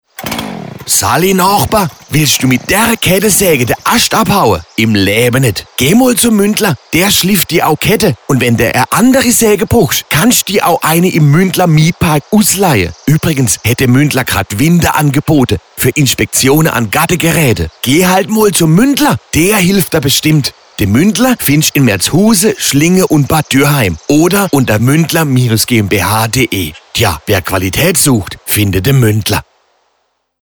Badischer Werbesprecher - Synchronsprecher
Sie sind auf der Suche nach einer badischen Werbestimme?
Muendler-Kettensaege-30s.mp3